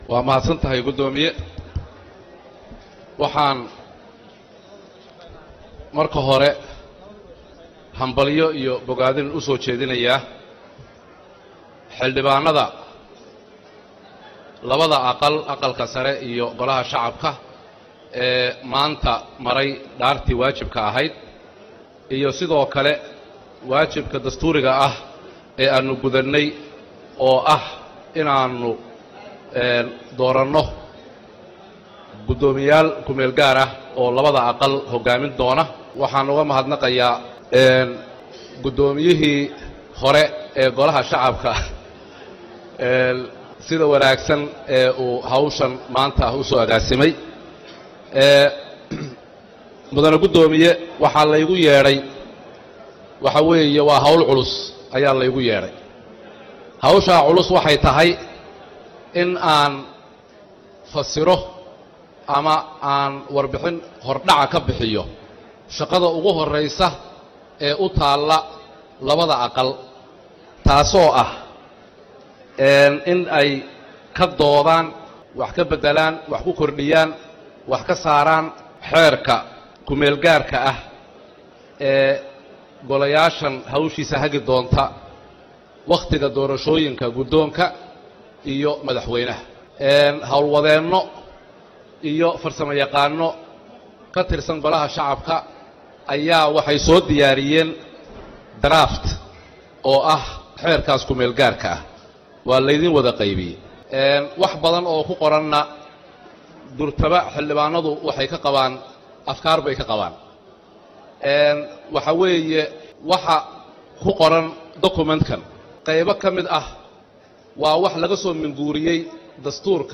Barlamaanka Jamhuuriyadda Federaalka Soomaaliya ayaa waxaa hor keenay  Habraaca Doorashada, iyadoo uu u akhriyey Xildhibaan Mahad Cawad oo ahaa Gudoomiye Ku xigeenkii labaad ee Baarlamaankii DFS ee waqtigiisa dhamaaday.